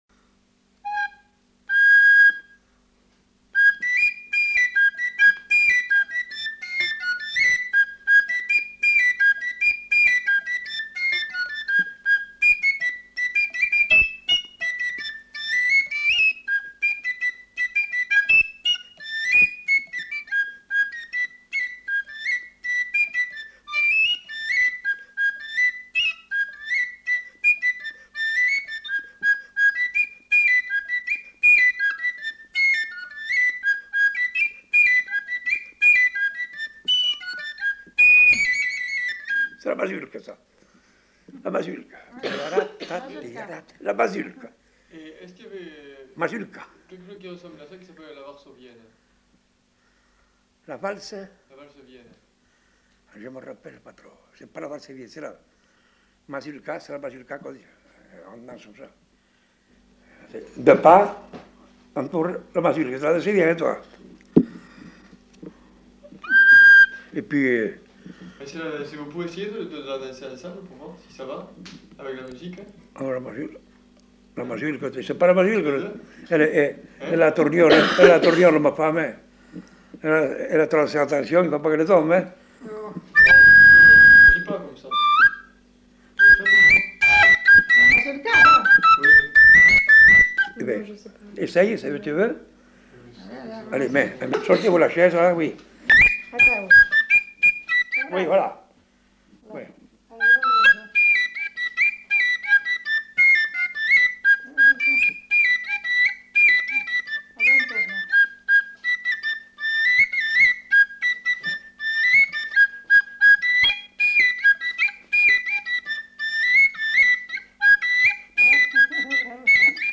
Aire culturelle : Bazadais
Genre : morceau instrumental
Instrument de musique : flûte à trois trous
Danse : mazurka